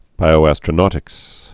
(bīō-ăstrə-nôtĭks)